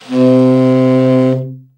BRA_TEN SFT    3.wav